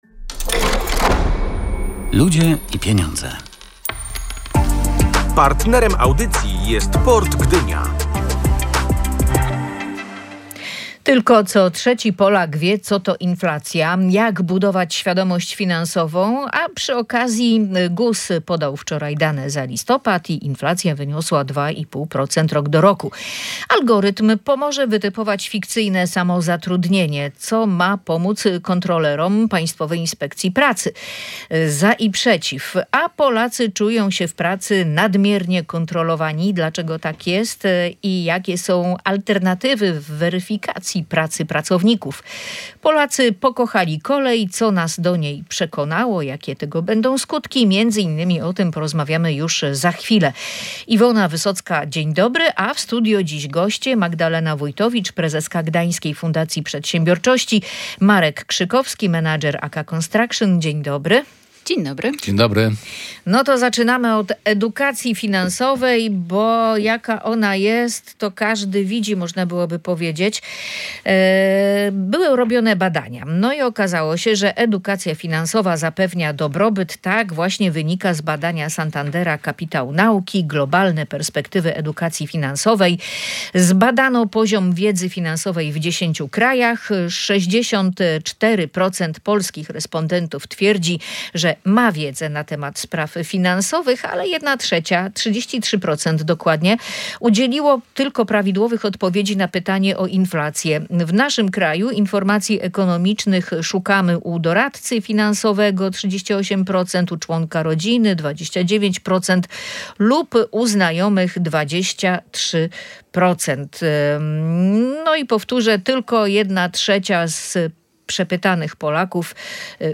Aż 38 proc. polskich ankietowanych skarży się na nadmierną kontrolę w pracy – wynika z międzynarodowego badania ADP. Na ten temat dyskutowali goście audycji „Ludzie i Pieniądze”